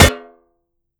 Hit_Metal 01.wav